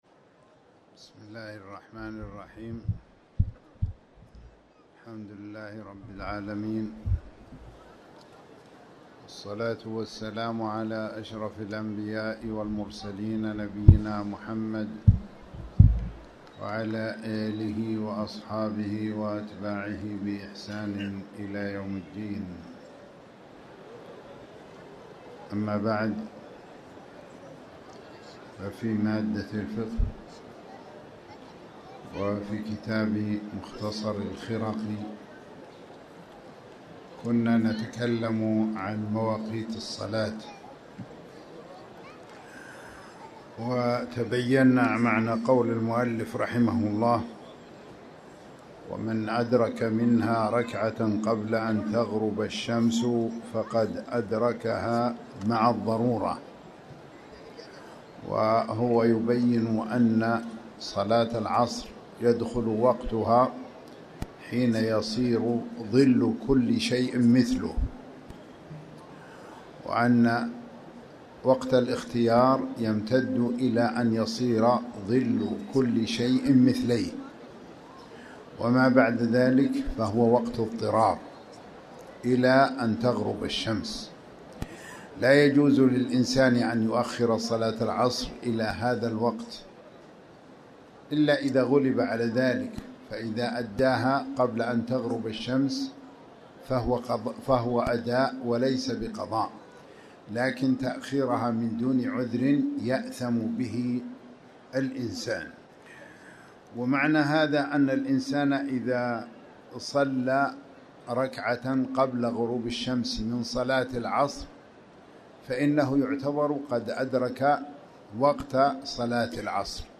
المكان: المسجد الحرام
20rbya-alawl-wqt-slah-alasr.mp3